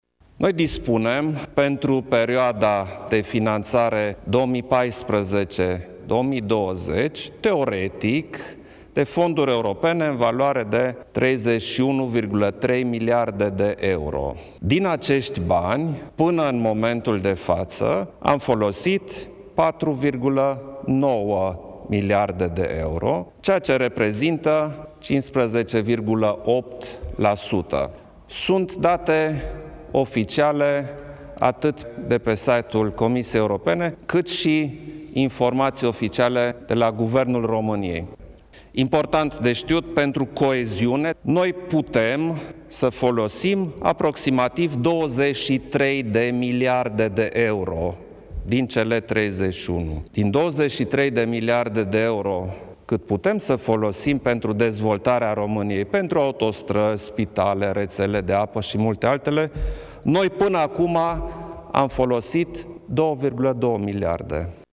Preşedintele Klaus Iohannis a declarat, cu puţin timp în urmă, România dispune pentru perioada 2014 – 2020 de fonduri în valoare de 31,3 miliarde de euro şi că din aceşti bani ţara noastră a folosit doar 4,9 miliarde de euro, adică 15,8%: